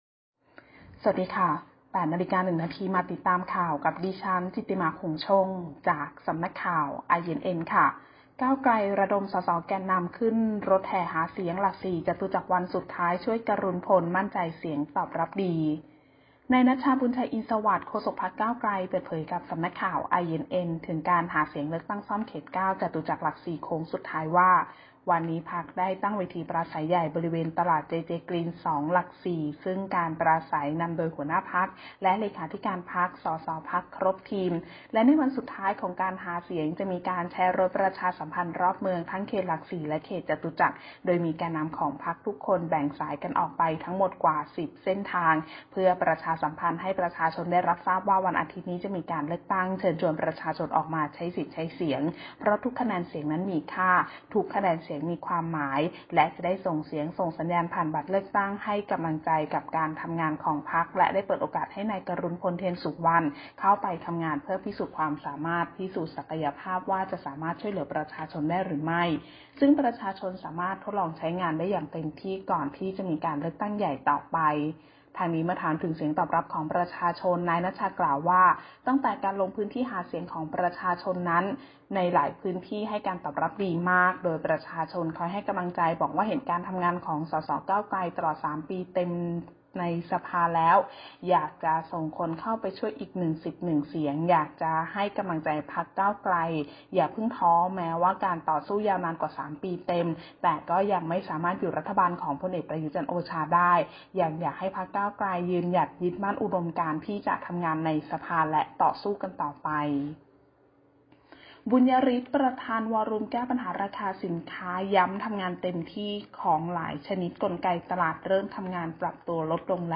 ข่าวต้นชั่วโมง 08.00 น.